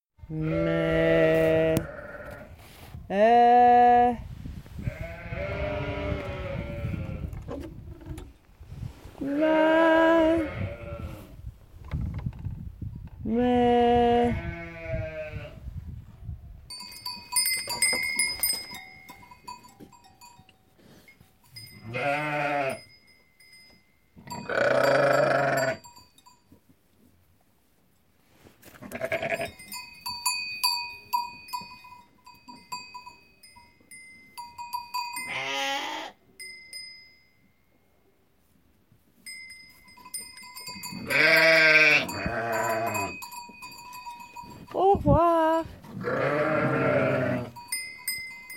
Moutons : rencontre